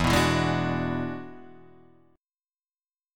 E9 chord {0 2 0 1 0 2} chord